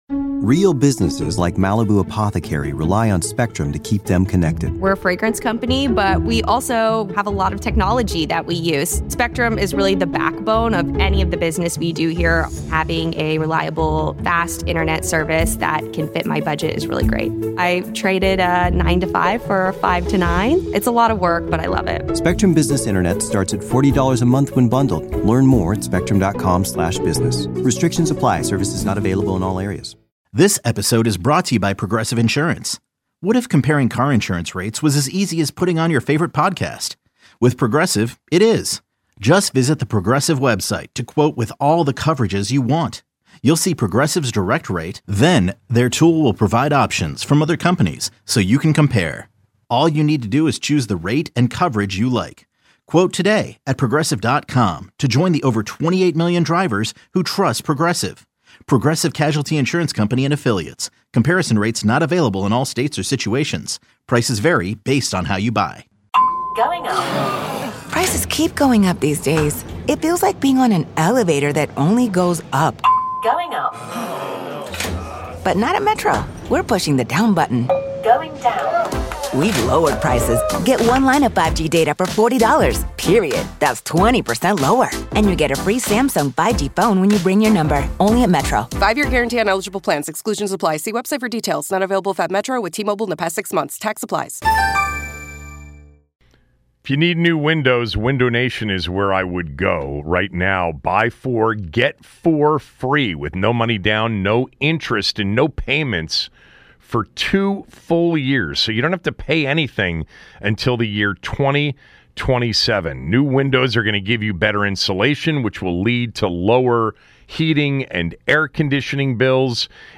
Callers give their ideal 1st rd pick for Commanders
Callers give their ideal position they want the Commanders to draft in the first round of the NFL Draft.